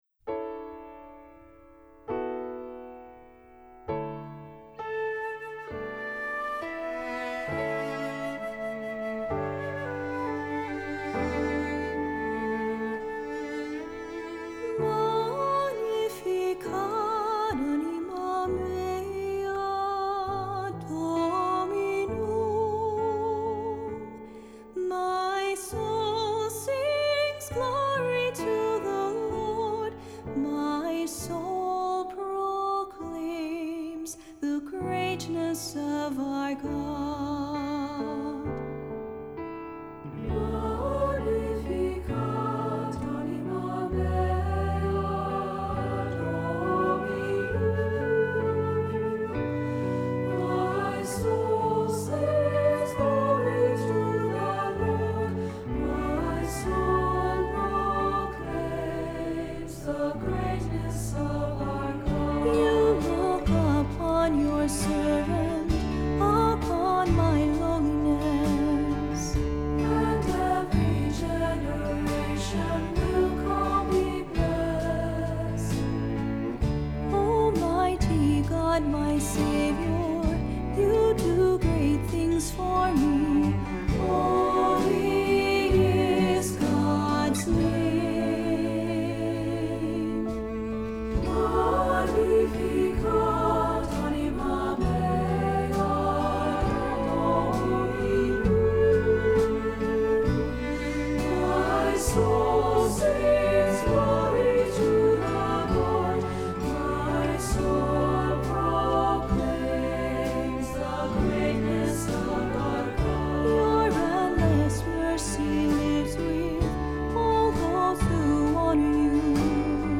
Accompaniment:      Keyboard
Music Category:      Christian
For cantor or soloist.